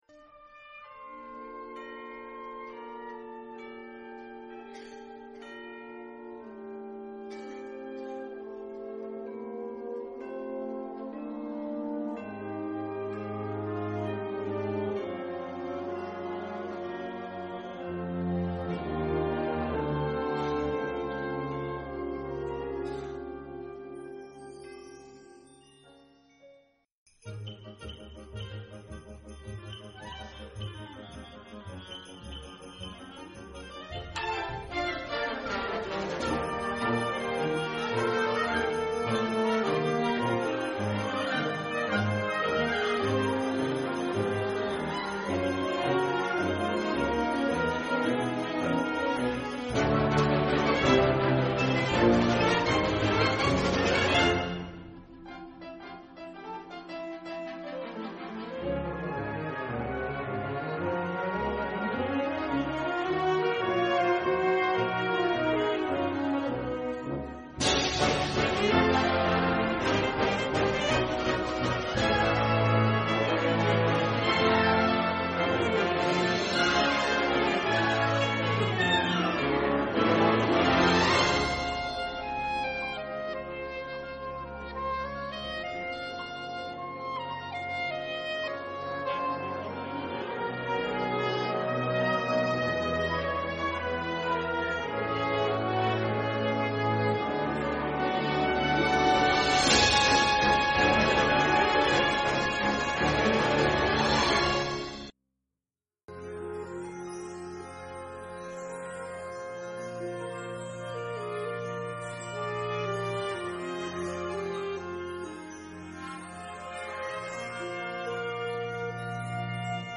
Genre musical : Classique
Collection : Harmonie (Orchestre d'harmonie)
Oeuvre pour orchestre d’harmonie.